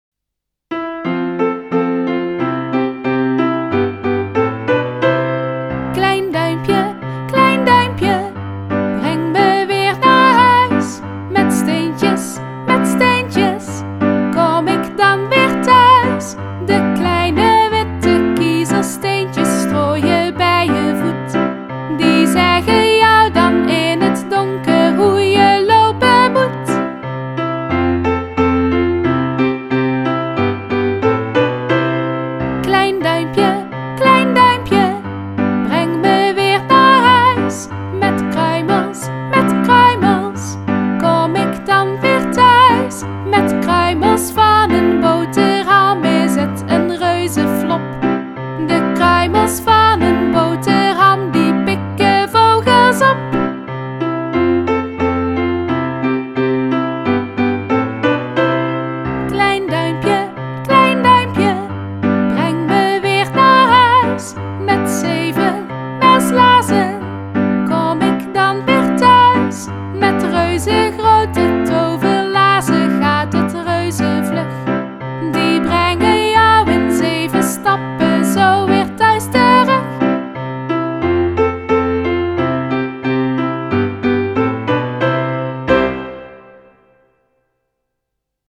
kleinduimpje_metzang.mp3